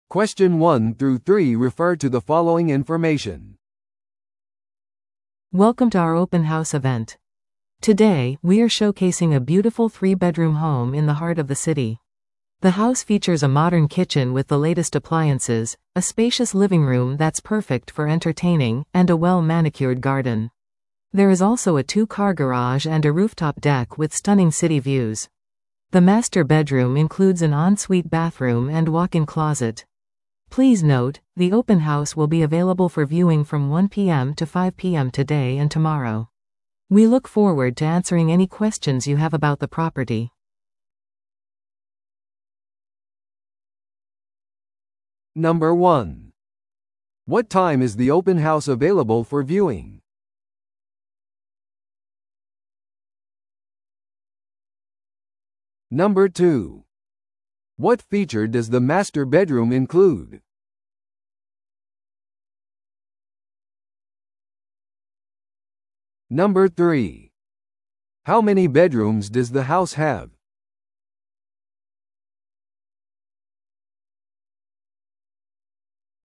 TOEICⓇ対策 Part 4｜オープンハウスの紹介 – 音声付き No.058